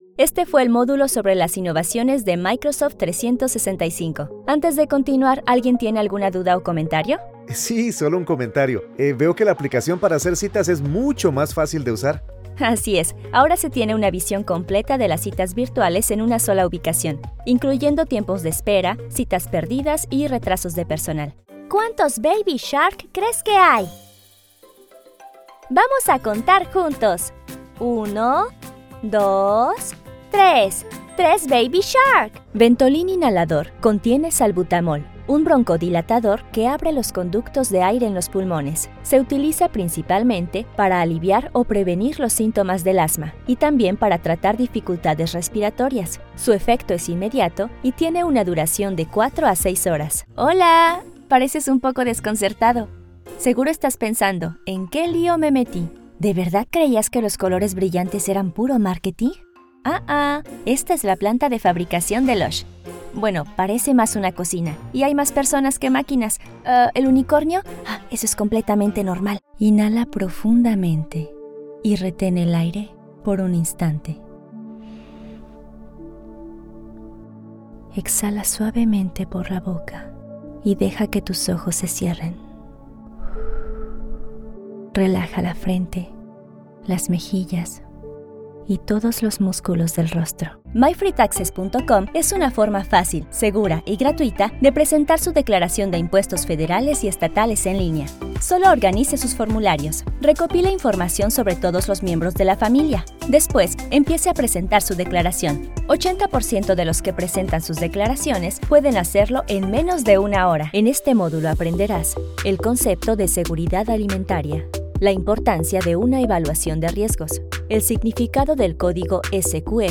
Spanisch (Lateinamerikanisch)
Junge, Natürlich, Freundlich, Sanft, Corporate
E-learning
Her accent is known to be “neutral” or “international”.